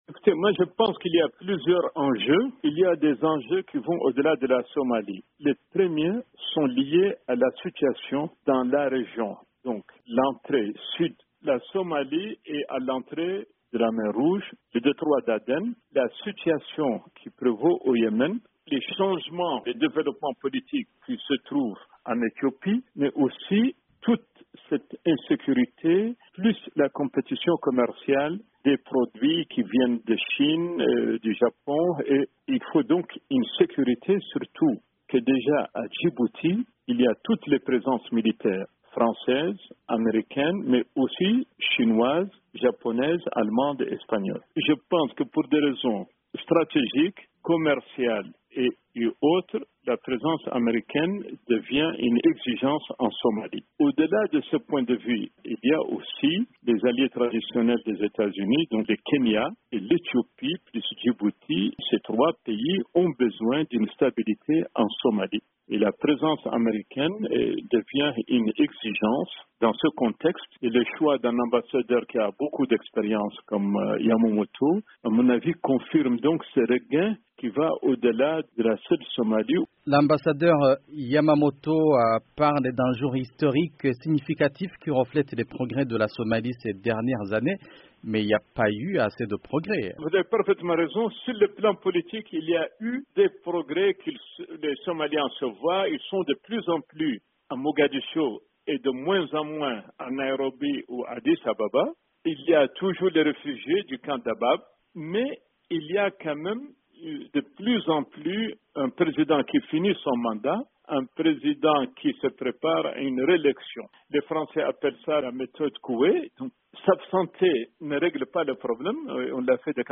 L'analyse d'Ahmedou Ould Abdallah, spécialiste en matière de sécurité.